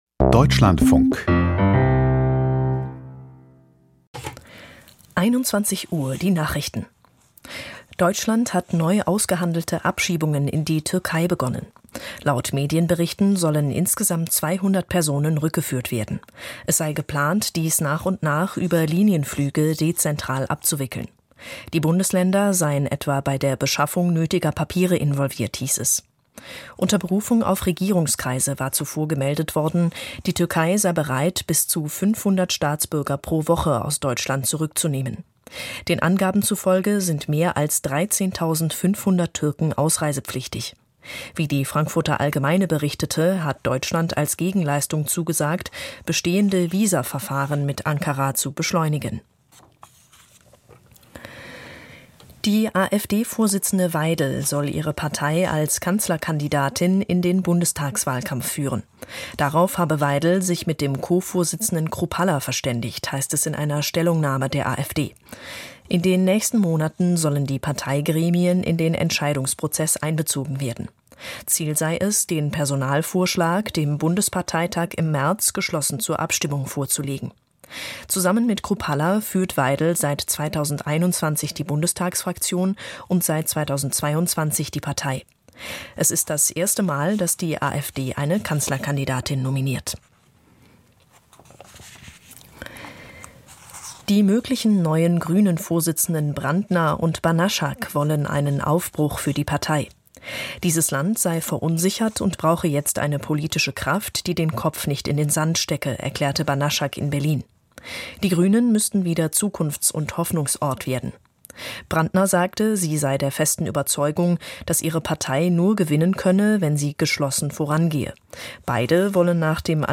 Kommentar zum Rentenpaket II - Vorhaben der Ampel lösen das Problem nicht - 27.09.2024